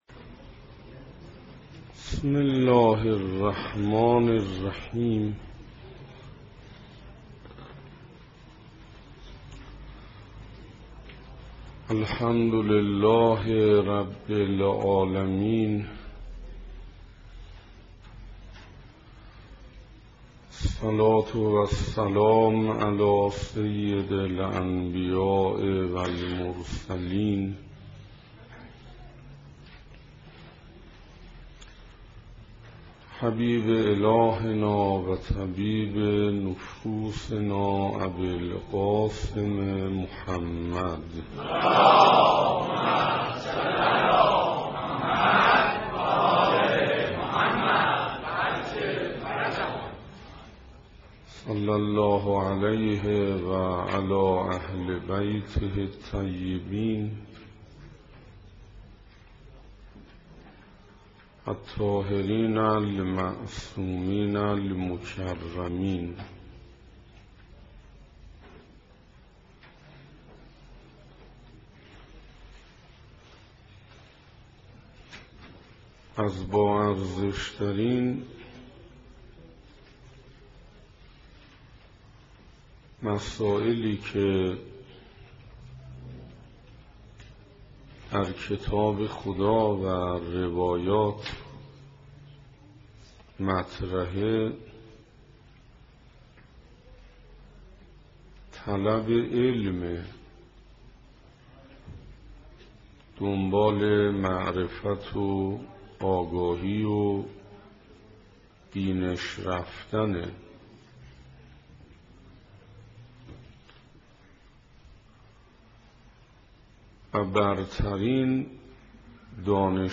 سخنراني بيست و دوم